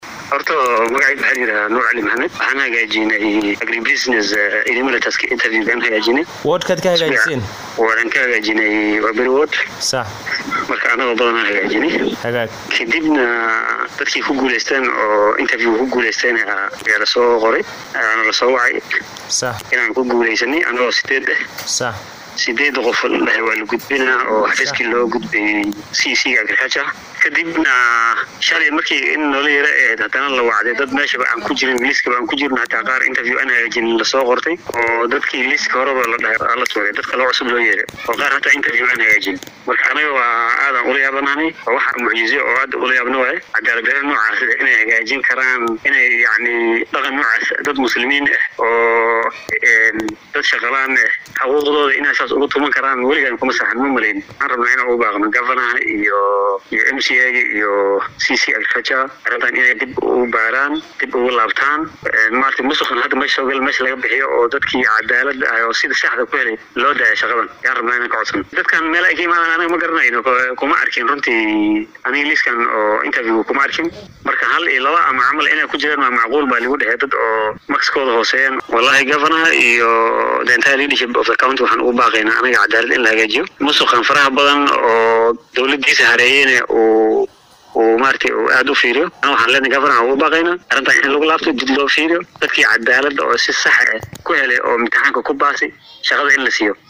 Mid ka mid ah dhallinyaradan ayaa khadka taleefoonka ugu warramay